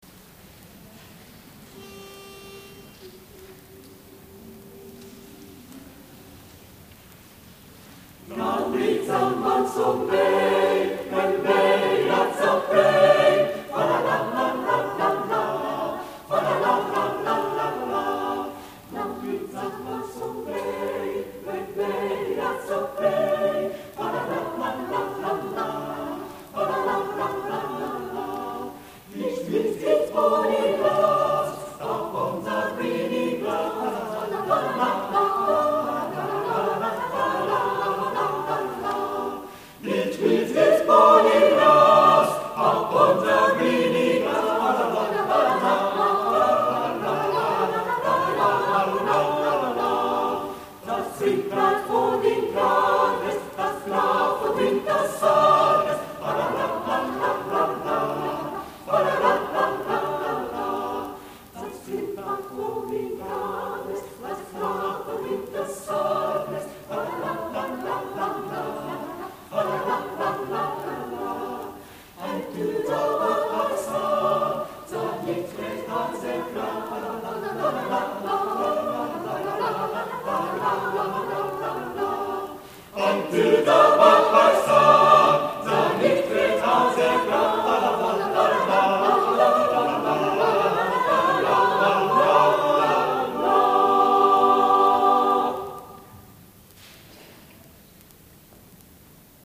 第33回野田市合唱祭
野田市文化会館